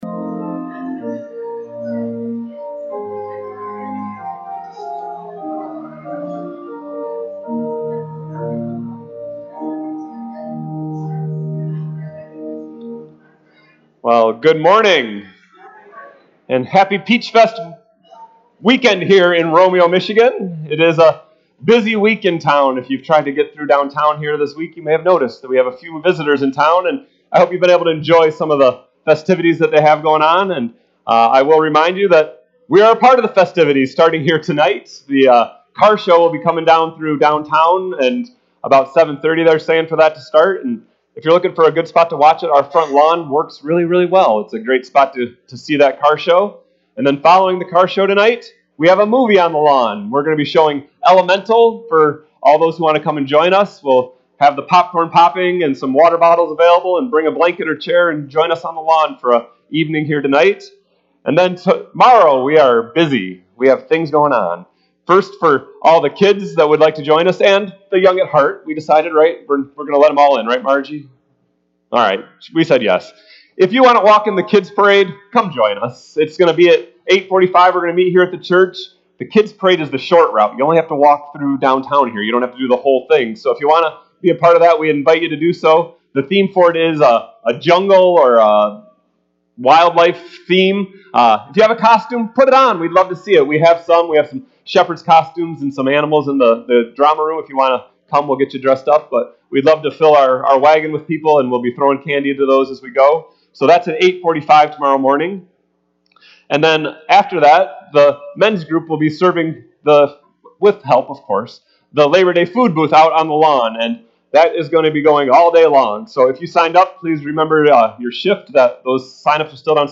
RUMC-service-Sept-3-2023.mp3